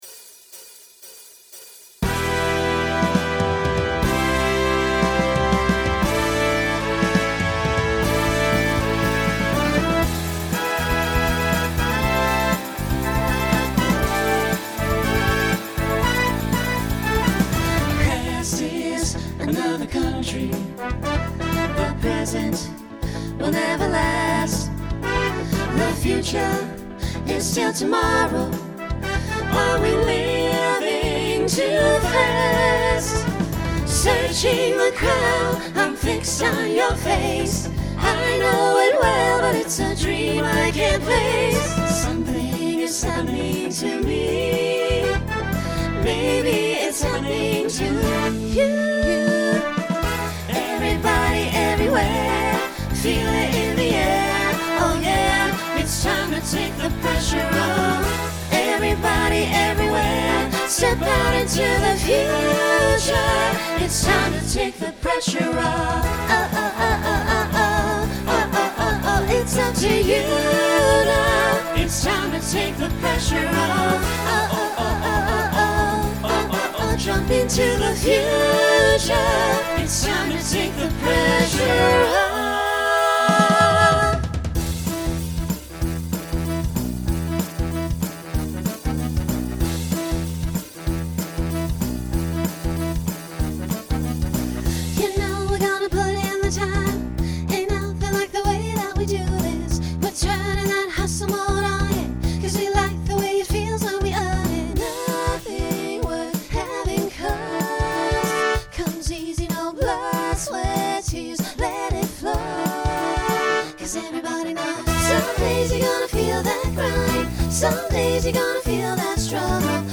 Starts with a quartet, then SSA, then SATB.
Pop/Dance , Swing/Jazz
Transition Voicing Mixed